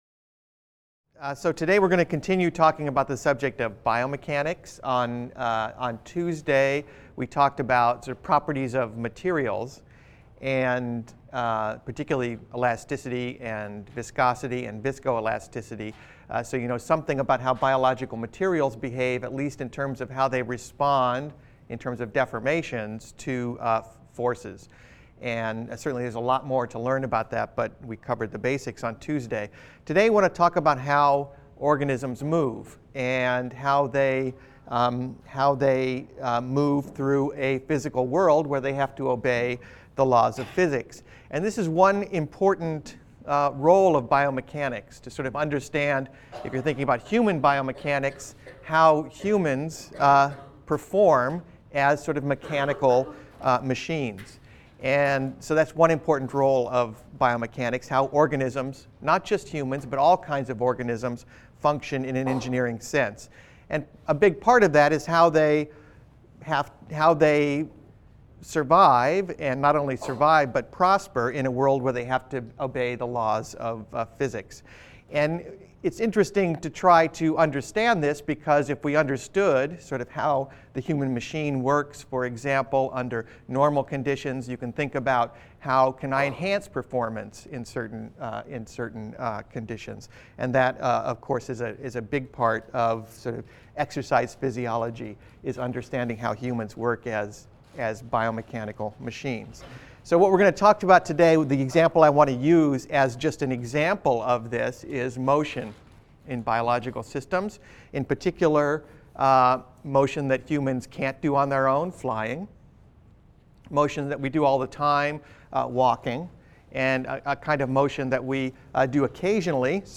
BENG 100 - Lecture 19 - Biomechanics and Orthopedics (cont.) | Open Yale Courses